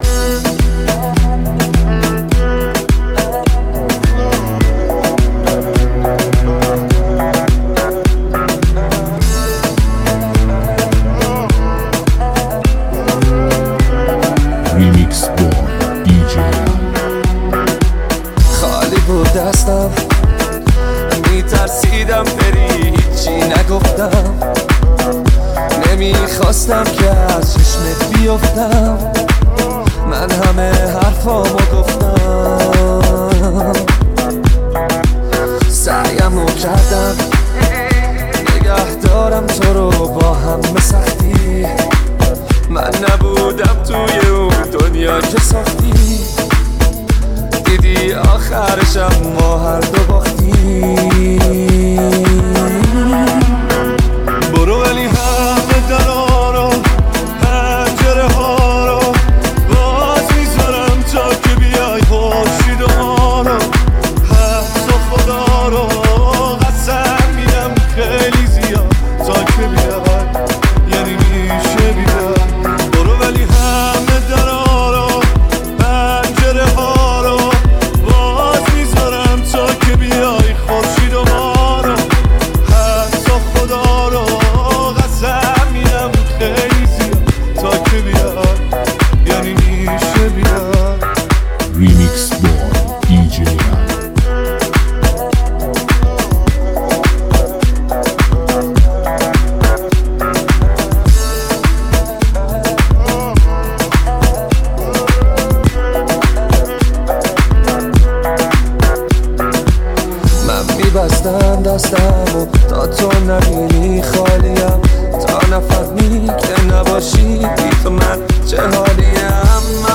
لذت بردن از موسیقی پرانرژی و بیس قوی، هم‌اکنون در سایت ما.
با بیس قوی و ریتم جذاب